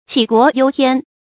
杞国忧天 qǐ guó yōu tiān
杞国忧天发音